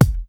HOTKICK'.WAV